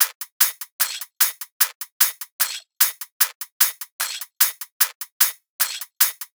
VR_top_loop_builtinsnarelayer_150.wav